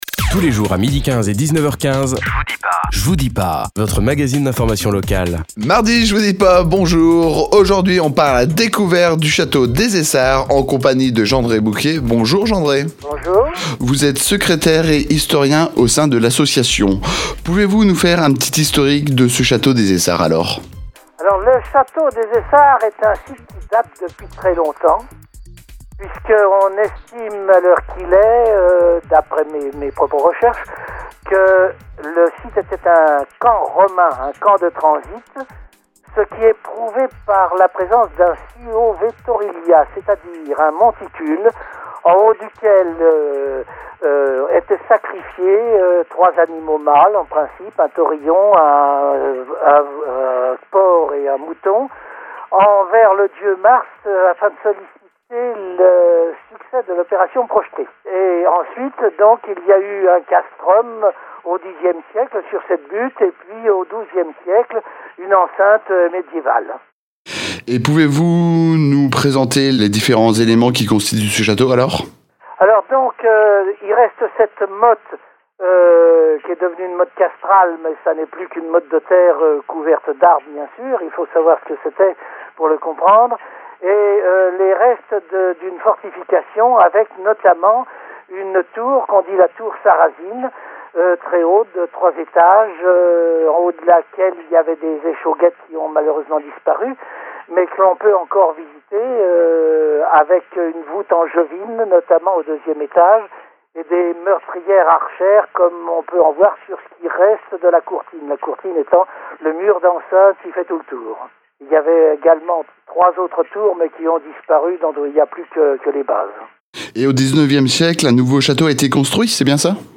Débat / table ronde